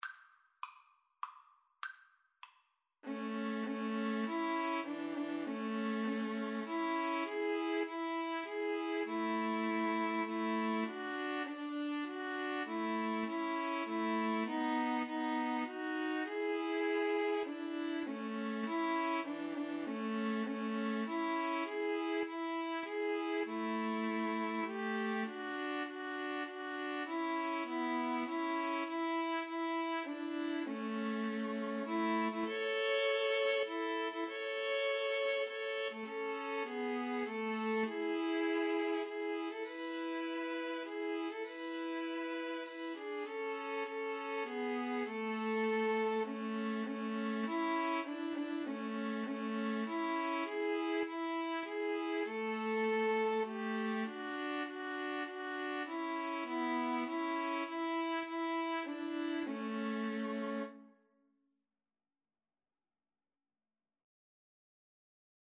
Free Sheet music for 2-violins-viola
3/4 (View more 3/4 Music)
A major (Sounding Pitch) (View more A major Music for 2-violins-viola )
Traditional (View more Traditional 2-violins-viola Music)